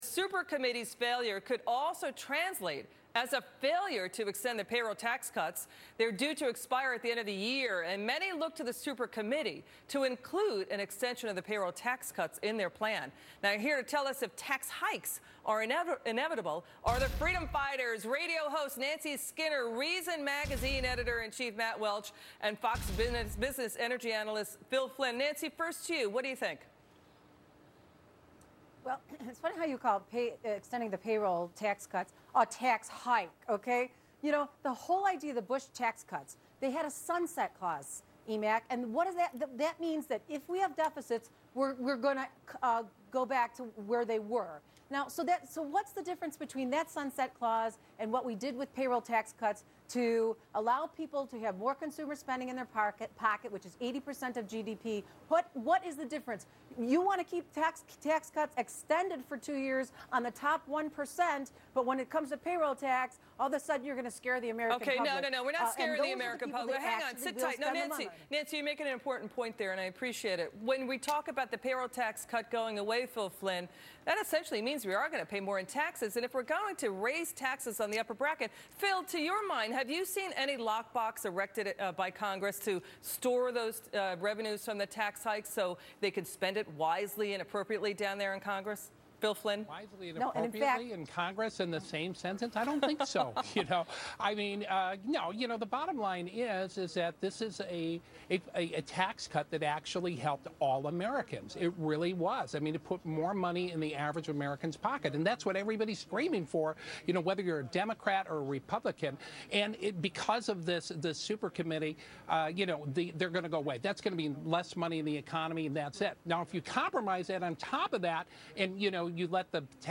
Reason Magazine Editor in Chief Matt Welch appeared on Freedom Watch With Judge Napolitano to discuss the treatment of what guest host Elizabeth MacDonald calls, "OWS pests", the economics of security surrounding the TSA and how the super committee's failure may jeopardize payroll tax cuts.